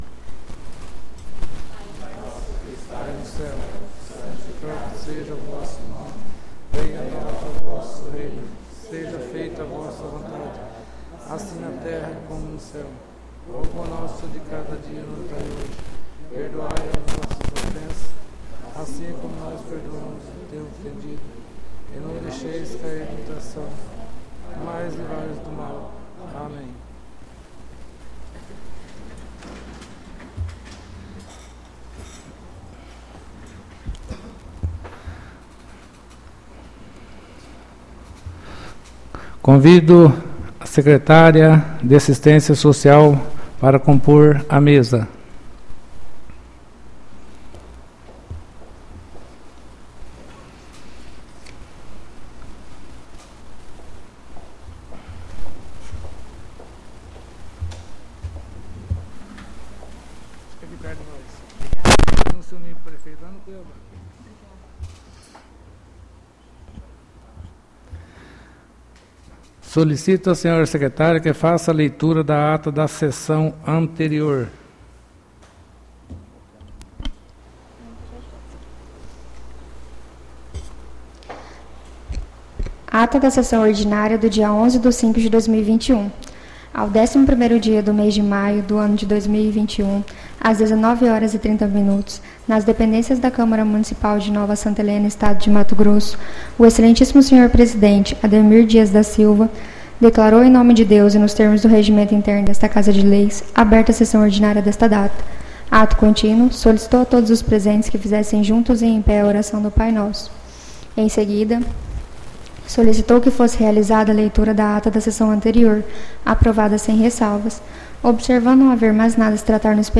ÁUDIO SESSÃO 18-05-21 — CÂMARA MUNICIPAL DE NOVA SANTA HELENA - MT